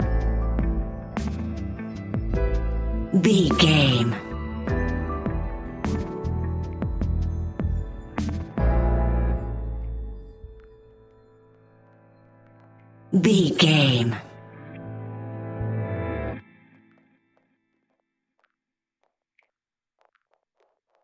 Epic / Action
Fast paced
In-crescendo
Thriller
Uplifting
Ionian/Major
A♭
hip hop